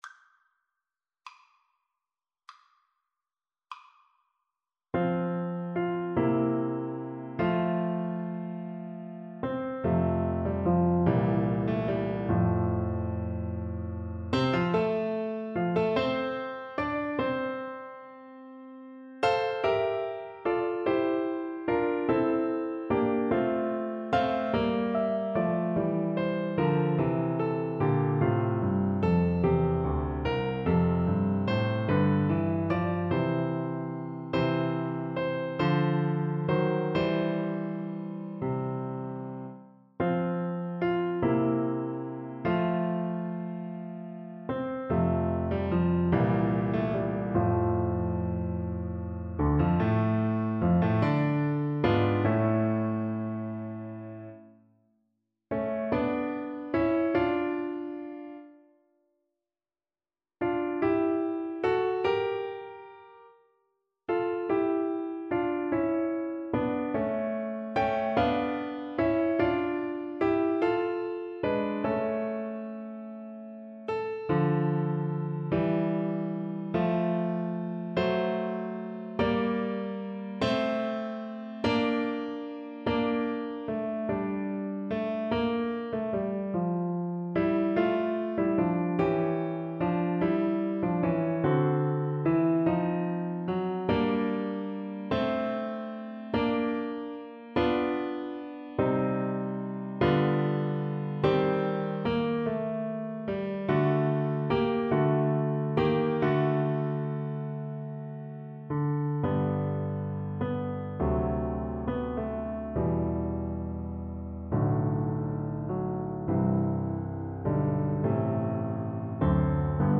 12/8 (View more 12/8 Music)
Classical (View more Classical Flute Music)